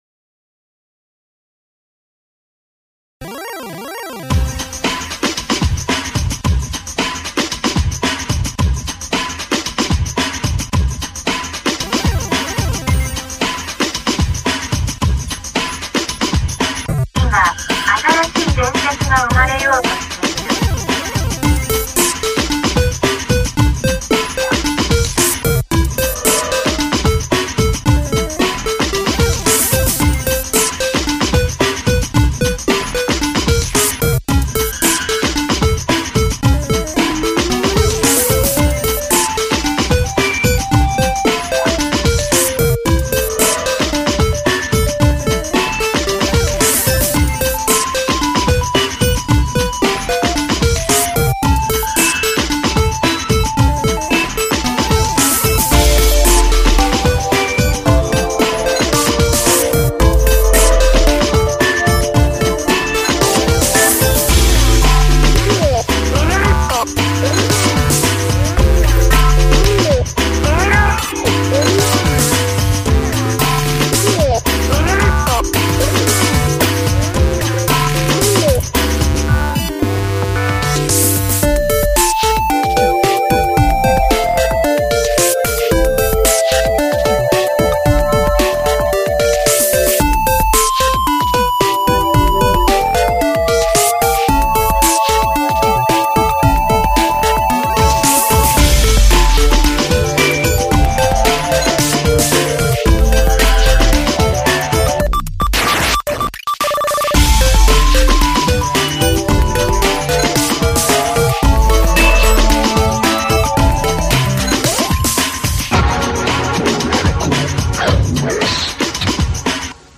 ただガチャガチャしたやかましいノイズなのではないか。
音量を小さめにしていただき(イヤホンは特に下げて)
Plogue Chipsounds
Magical8bitPlug
Voiceroid+
Mac OS X Voice Over(Kyoko/Samantha/Princess/Daniel)